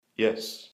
Вы найдете разные варианты: от радостного и энергичного до томного и шепотного «yes», записанные мужскими, женскими и детскими голосами.
Yes мужик спокойно